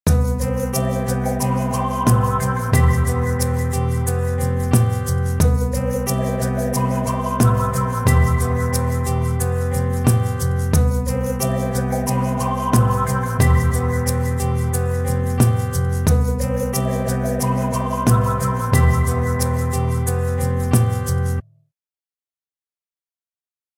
Nedan finns ljudfiler och tillhörande bilder för olika betoningar.
Trupp A Betoningar 2 (mp4)